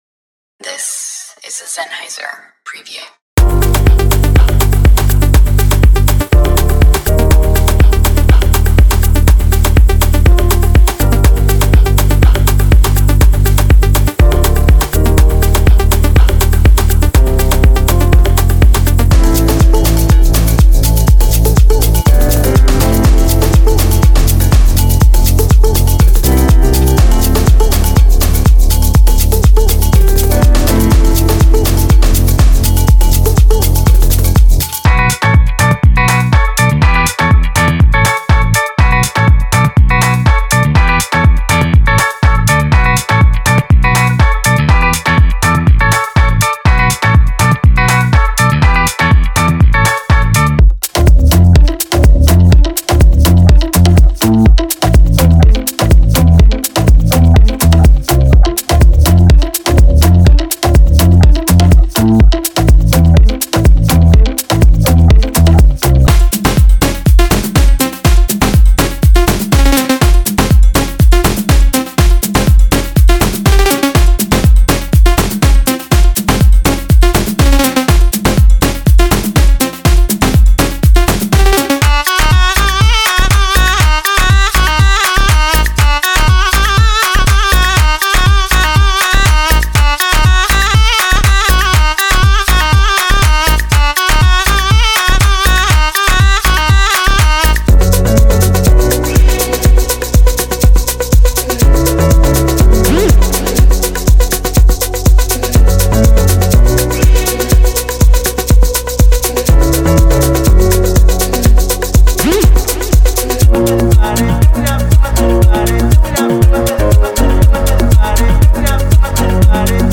Genre:Afro House
感情が自然に展開する本質—オーガニックで地に足がつき、美しくメロディックなサウンドです。
パーカッションはライブ録音されています。
シェイカーやドラム、手で演奏されたグルーヴにより、それぞれのリズムにスウィング感、余裕、自然な流れが生まれています。
スタイリッシュで洗練されつつ、制作には人間味が溢れています。
デモサウンドはコチラ↓